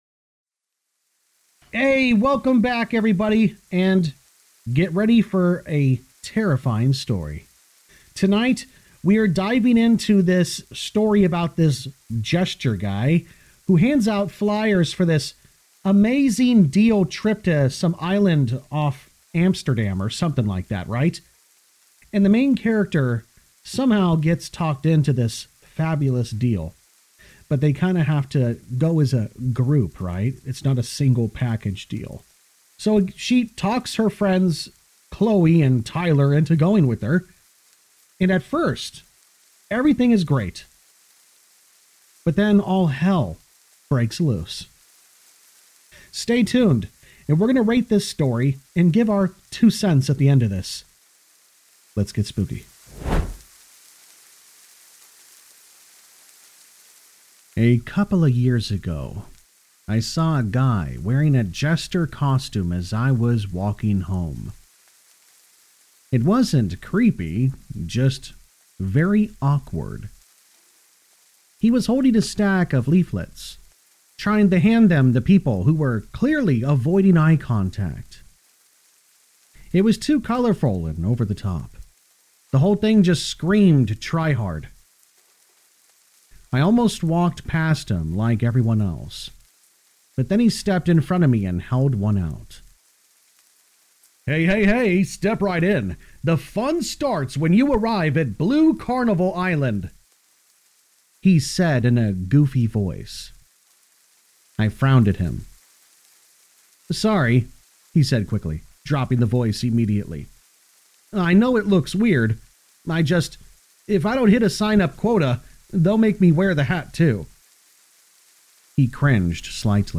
*** Real human voiced horror narrations, never Ai ***